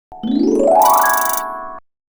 UI_SFX_Pack_61_6.wav